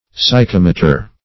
Psycho-motor \Psy`cho-mo"tor\, a. [Psycho- + motor.]